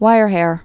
(wīrhâr)